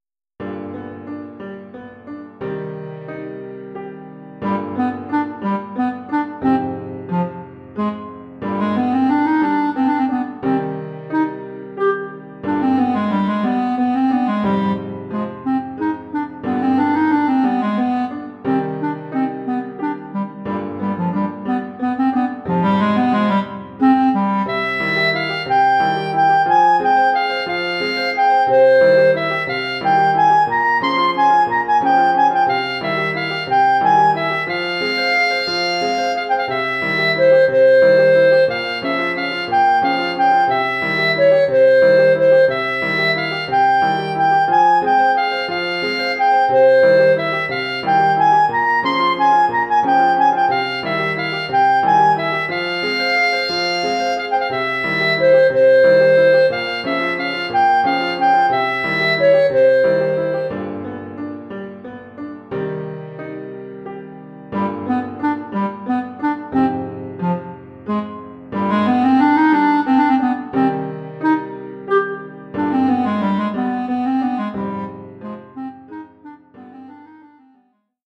Formule instrumentale : Clarinette et piano
Oeuvre pour clarinette et piano.